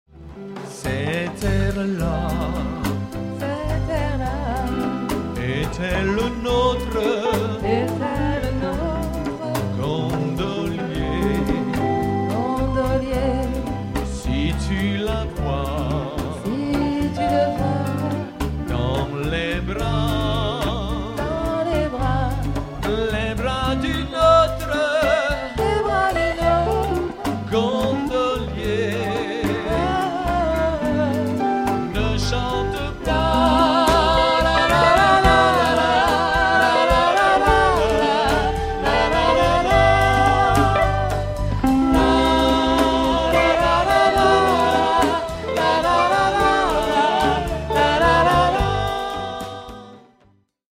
boléro